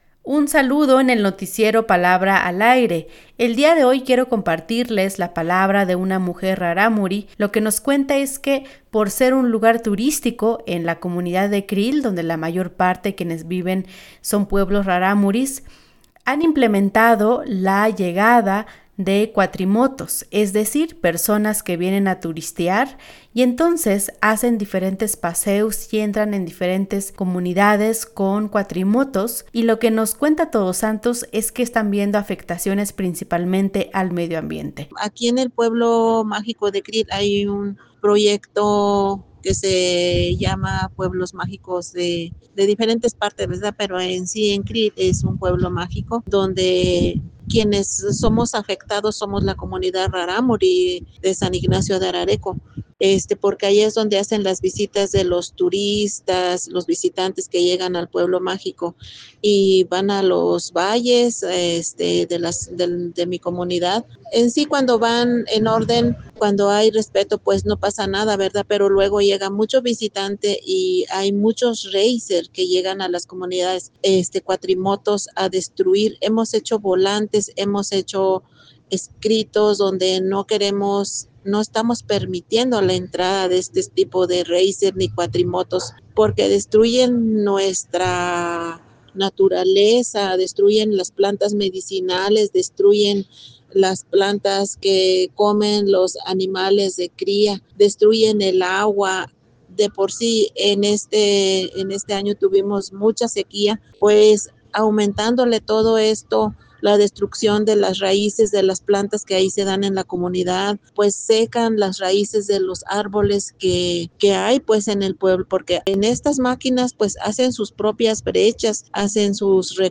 una mujer Rarámuri que nos habla más de esta situación.